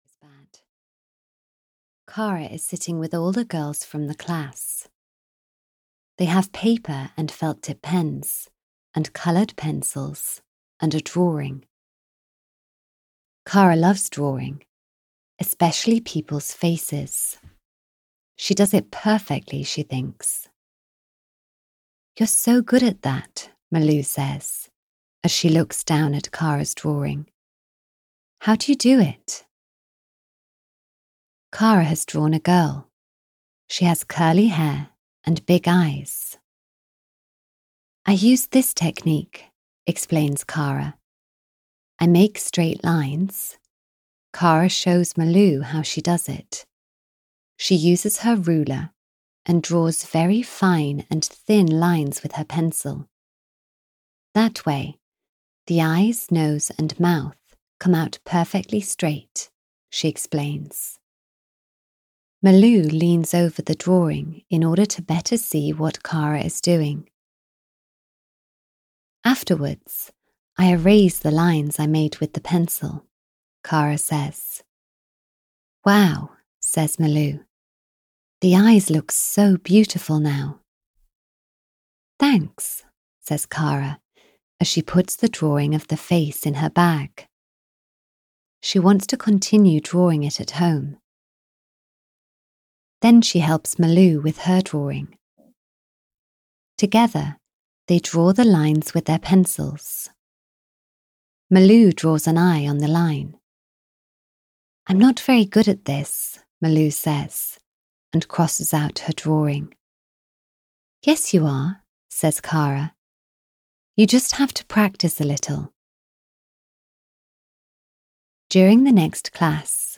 K for Kara 22 – You're Good Enough! (EN) audiokniha
Ukázka z knihy